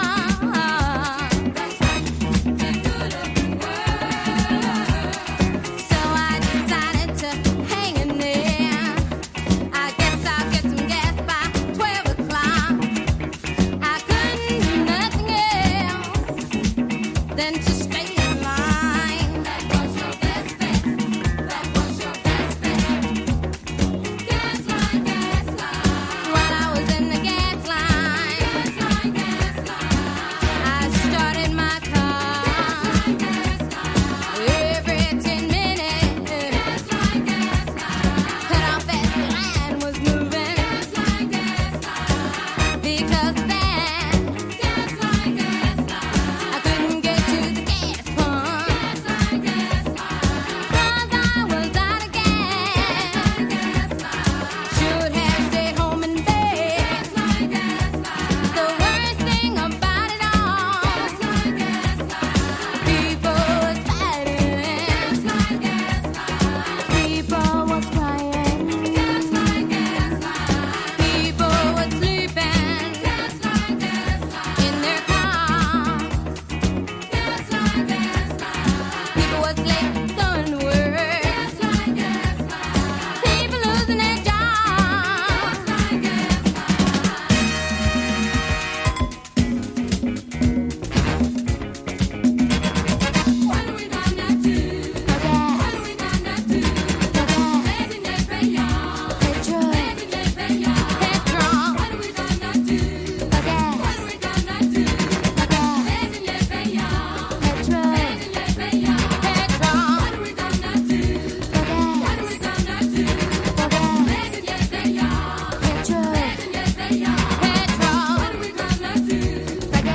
女性ヴォーカルをフィーチャーしたDJ的にもプレイしやすいグルーヴィーなアフロ・ディスコ〜ファンクを展開！